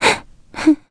Gremory-Vox_Sad_a.wav